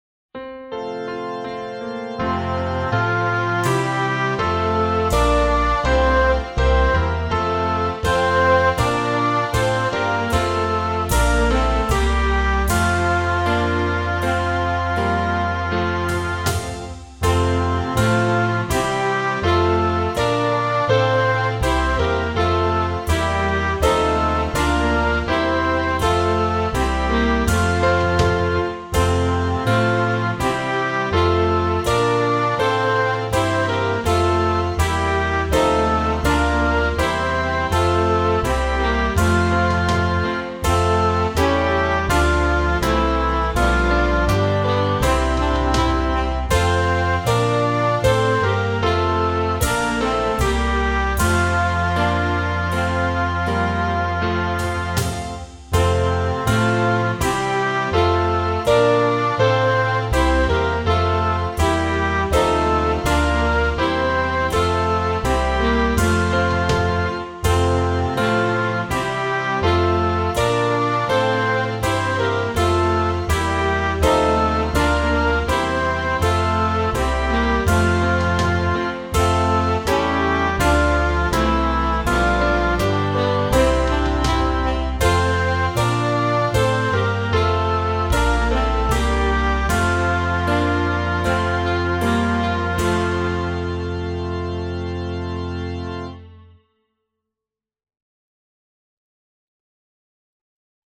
Musik & Ende
Ende Partangiangan